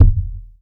impact_deep_thud_bounce_07.wav